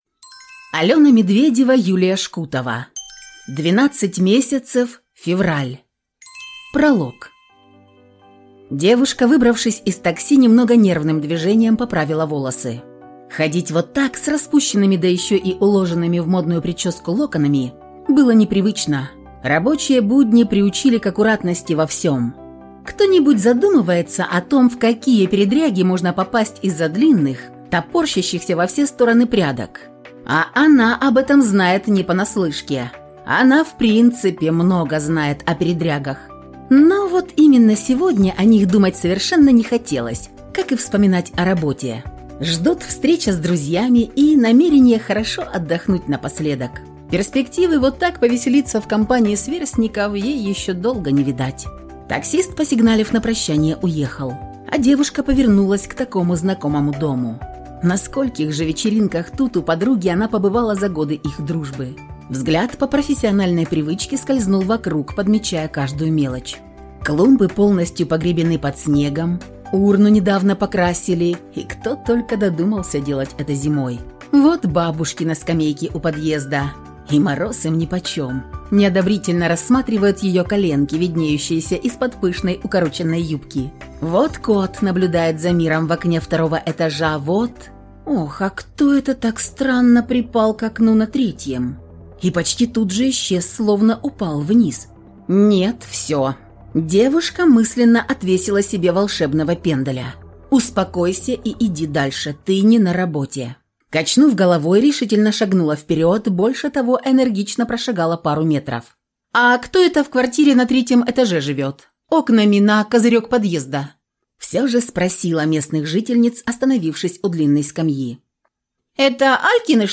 Аудиокнига Двенадцать Месяцев. Февраль | Библиотека аудиокниг